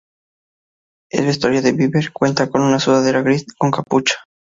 su‧da‧de‧ra
Pronúnciase como (IPA)
/sudaˈdeɾa/